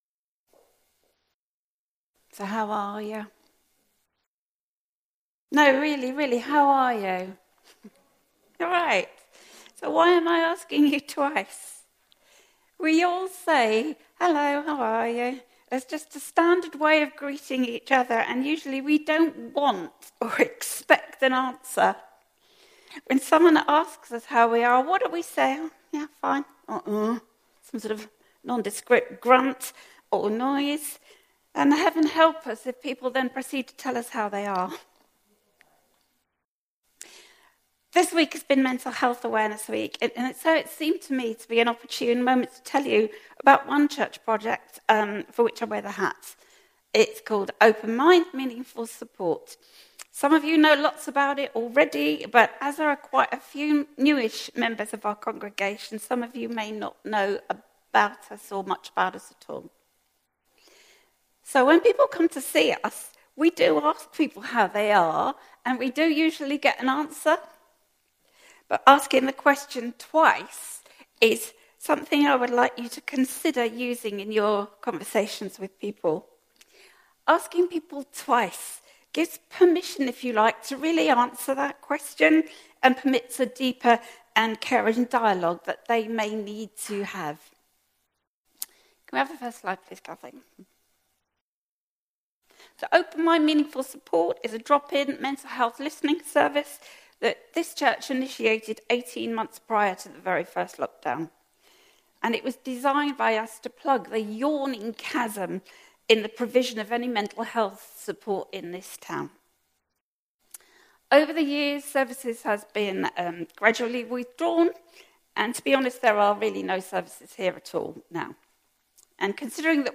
An audio version of the service is also available.